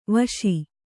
♪ vaśi